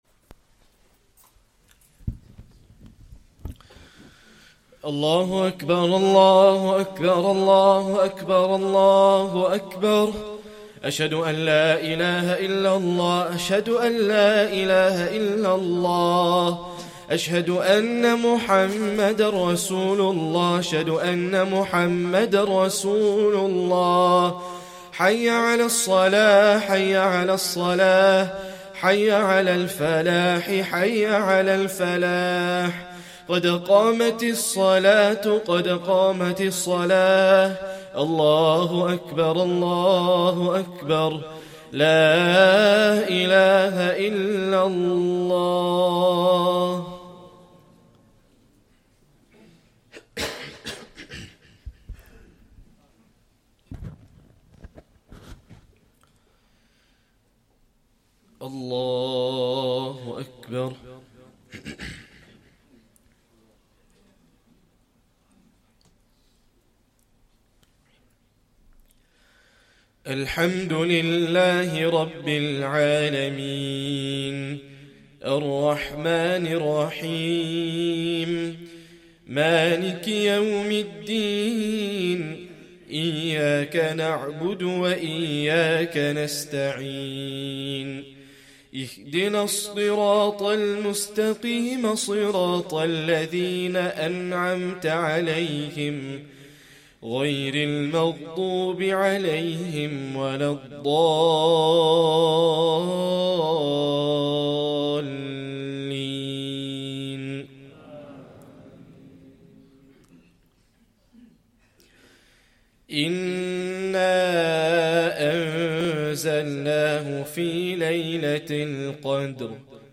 Esha + 1st Tarawih prayer - 24th Ramadan 2024